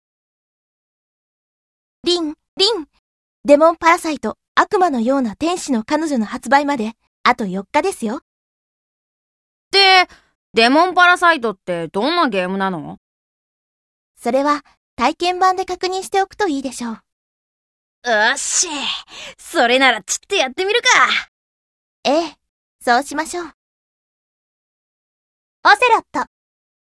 『デモンパラサイト～悪魔のような天使の彼女』 発売４日前カウントダウンボイス公開！